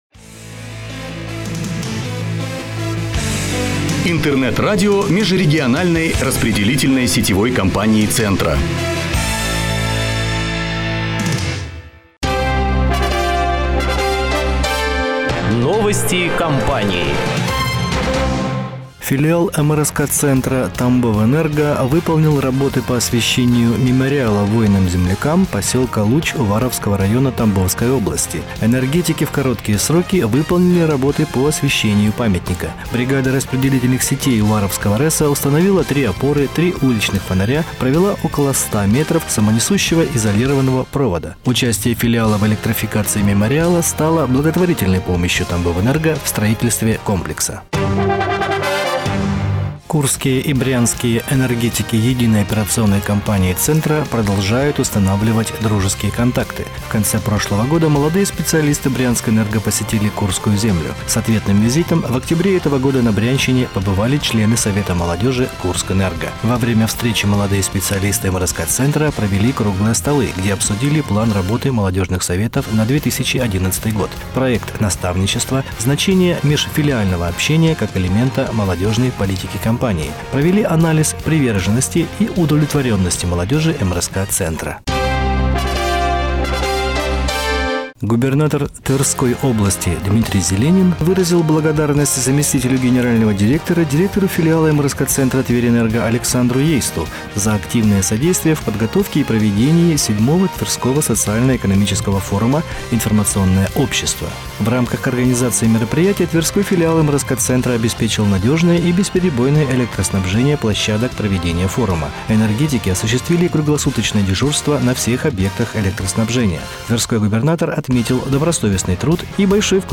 Выпуск новостей (mp3, 4 123Kb)